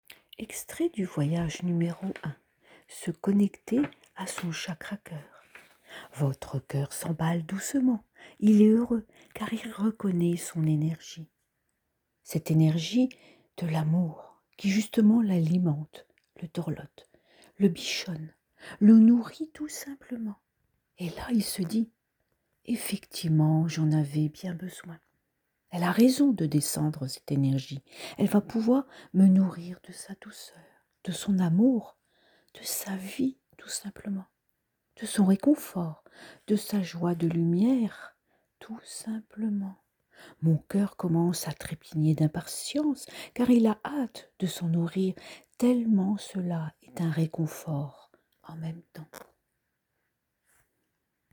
26.58 min de voyage méditatif